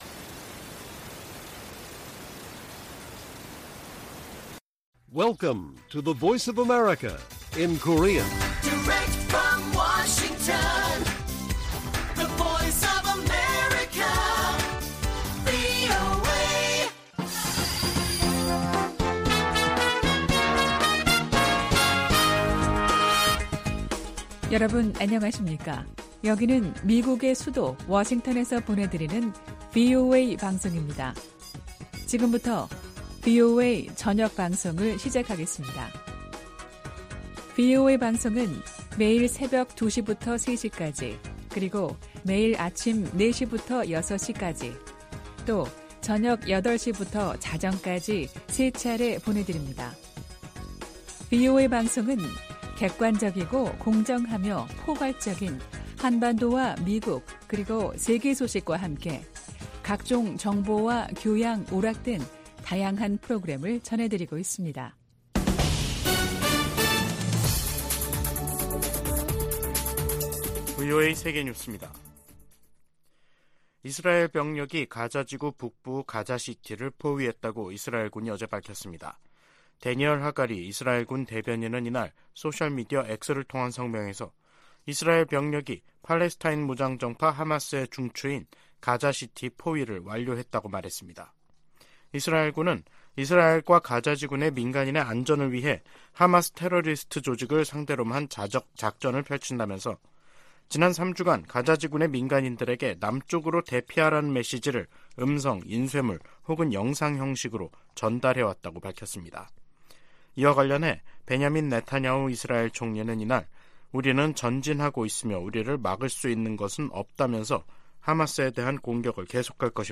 VOA 한국어 간판 뉴스 프로그램 '뉴스 투데이', 2023년 11월 3일 1부 방송입니다. 다음 주 한국을 방문하는 토니 블링컨 미 국무장관이 철통 같은 방위 공약을 강조할 것이라고 국무부가 밝혔습니다.